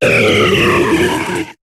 Cri de Regice dans Pokémon HOME.